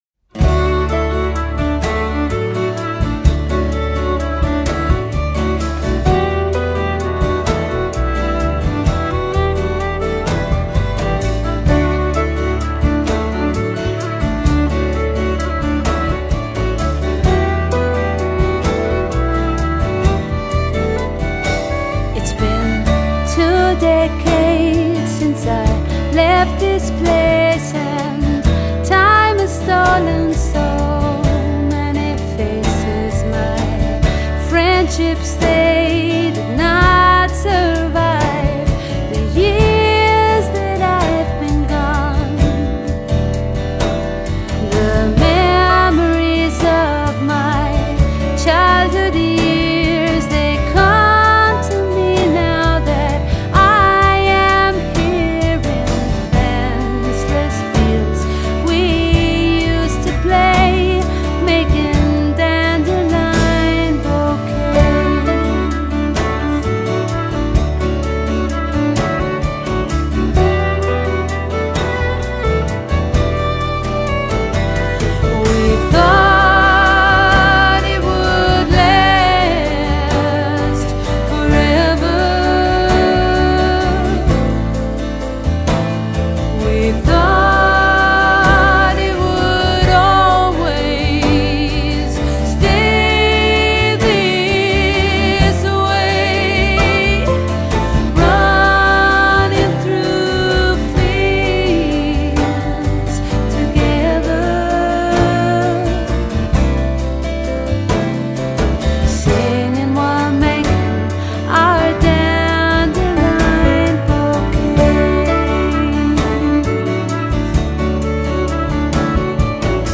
Singer-songwriter / Folk.